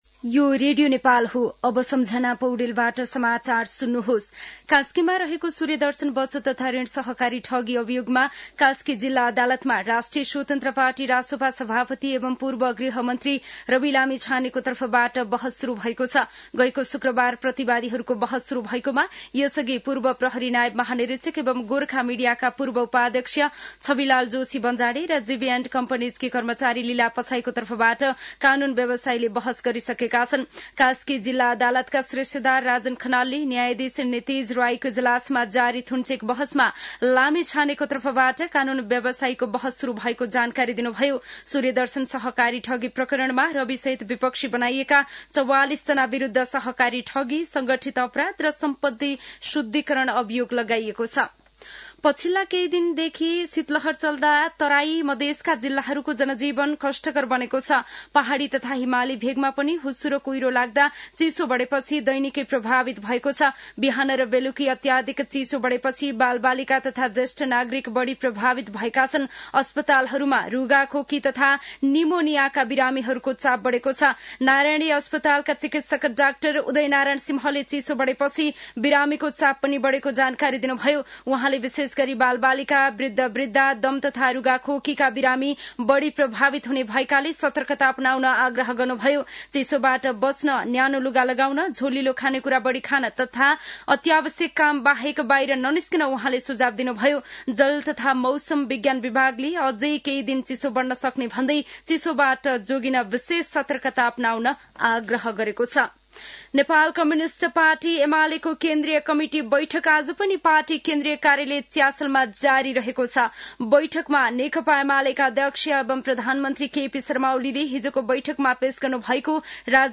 दिउँसो १ बजेको नेपाली समाचार : २३ पुष , २०८१
1-pm-news-.mp3